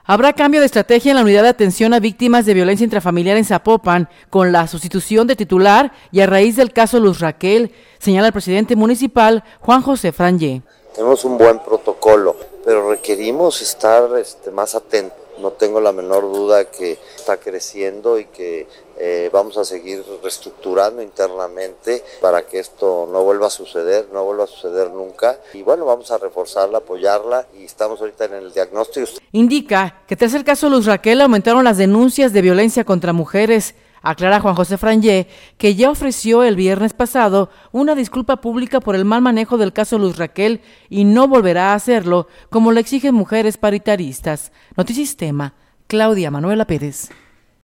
señala el presidente municipal, Juan José Frangie.